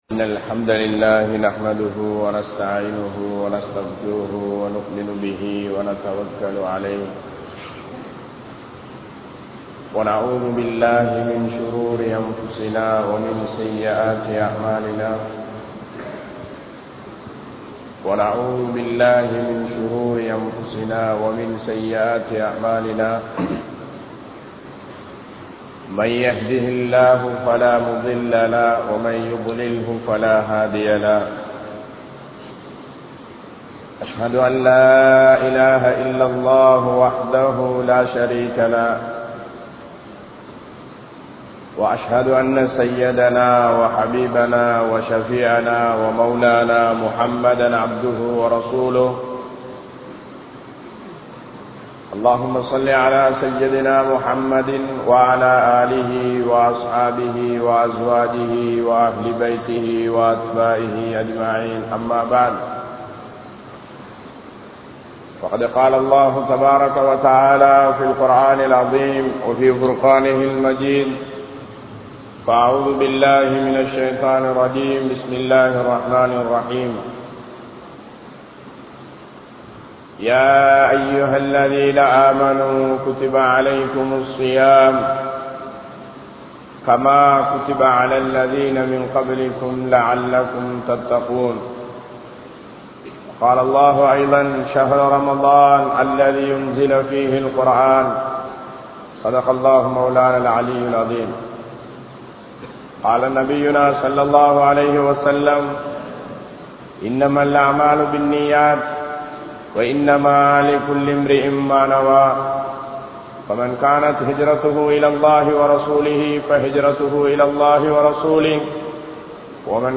Ramalaanin Noakkam Enna? (ரமழானின் நோக்கம் என்ன?) | Audio Bayans | All Ceylon Muslim Youth Community | Addalaichenai